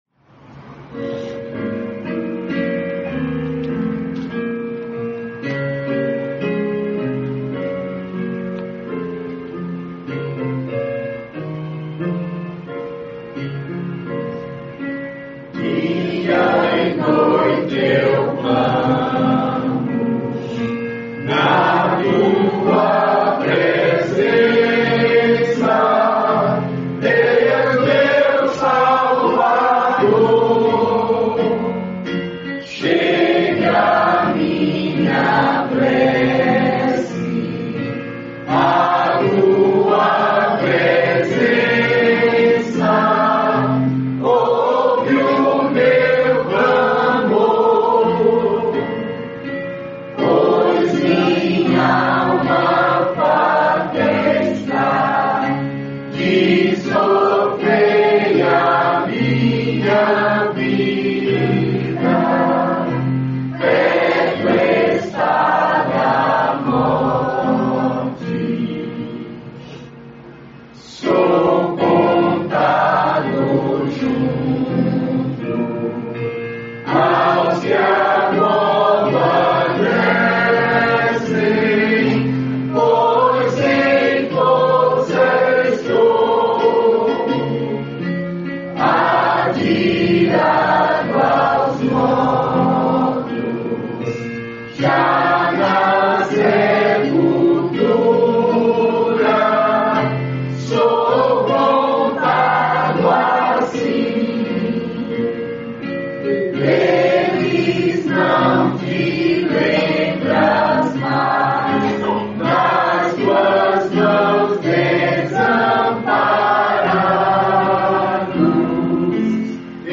Compositor: Johann Crüger, 1653
Harmonização: Johann Sebastian Bach, 1723
salmo_88B_cantado.mp3